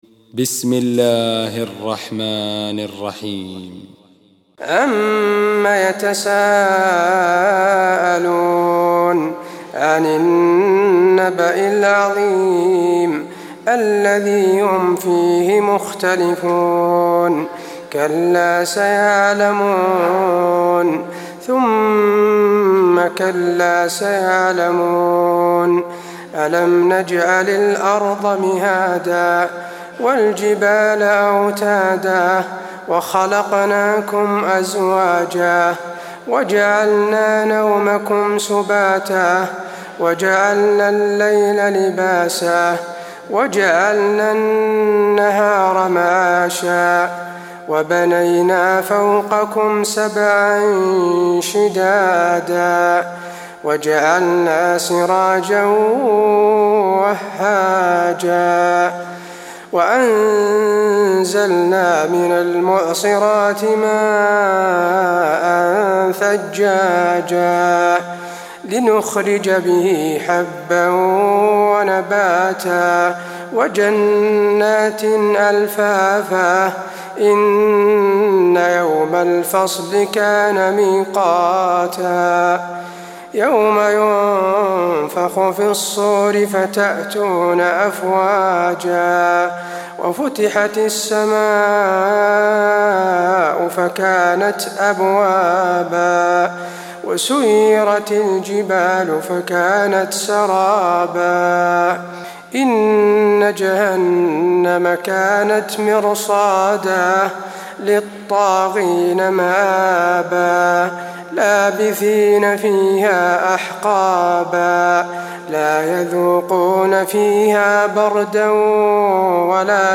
تراويح ليلة 29 رمضان 1423هـ من سورة النبأ الى الانفطار Taraweeh 29 st night Ramadan 1423H from Surah An-Naba to Al-Infitaar > تراويح الحرم النبوي عام 1423 🕌 > التراويح - تلاوات الحرمين